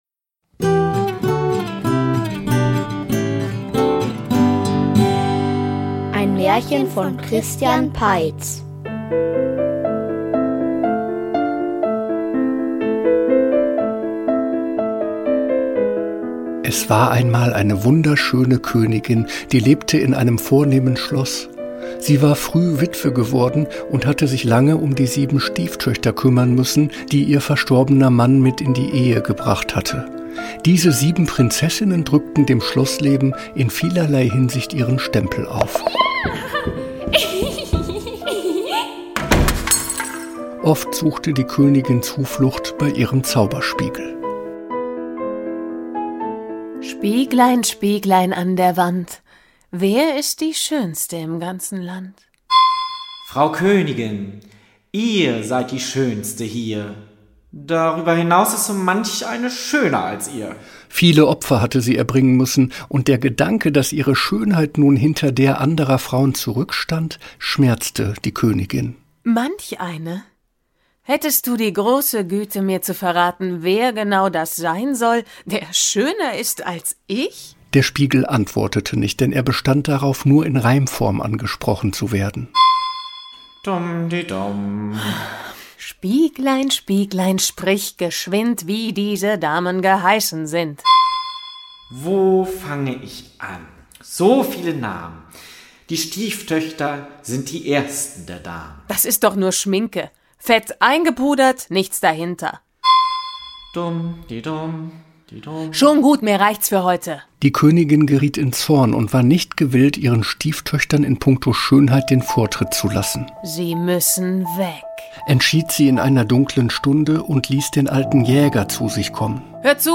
Schneewutzel --- Märchenhörspiel #48 ~ Märchen-Hörspiele Podcast